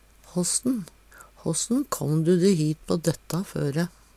håssen - Numedalsmål (en-US)